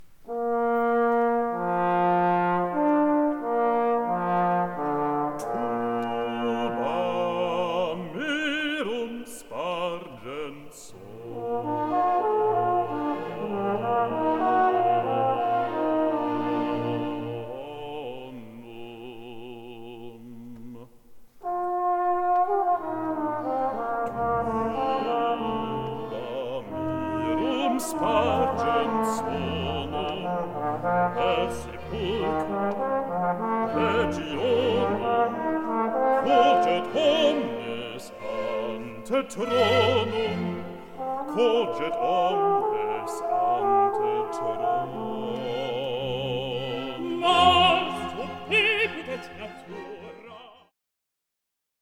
Bariton